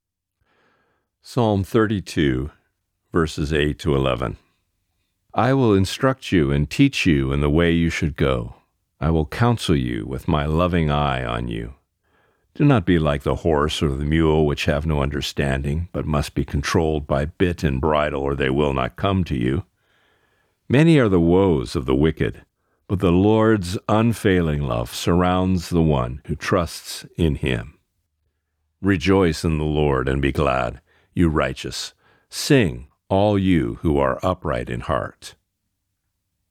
Today’s Reading: Psalm 32:8-11